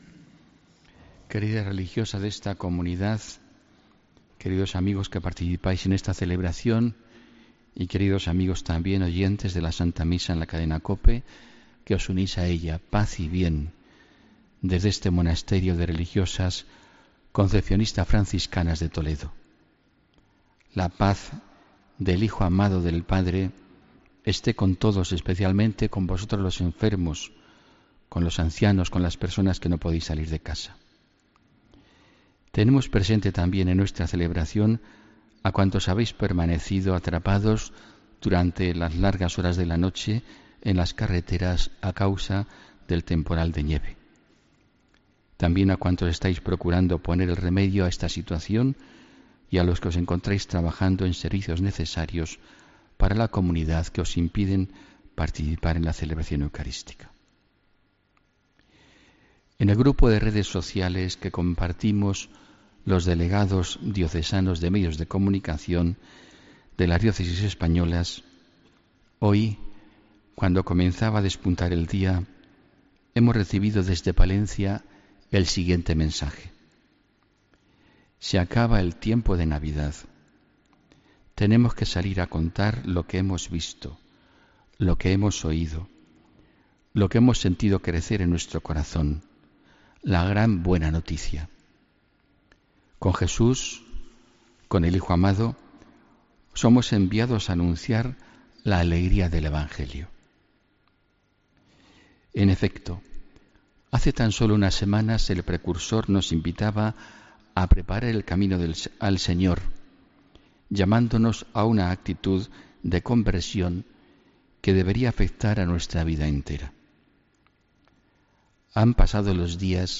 HOMILÍA 7 DE ENERO